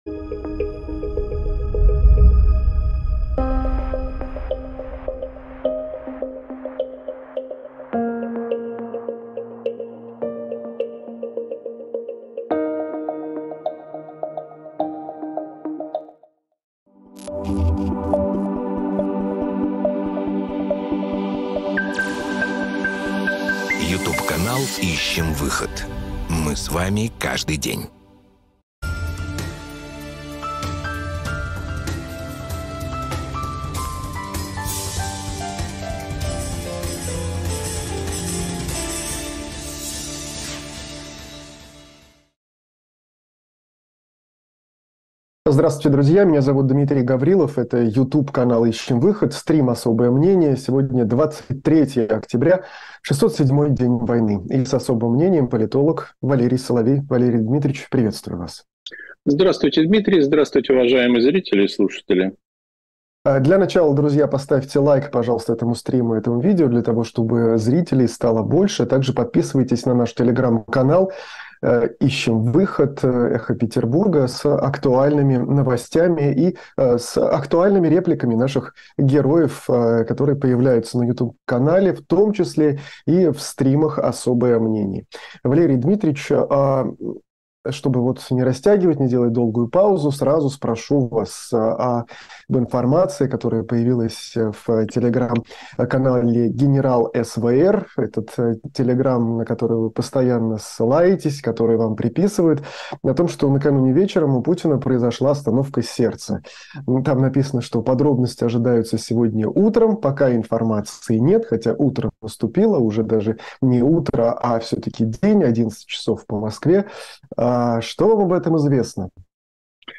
журналист
политолог